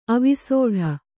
♪ avisōḍha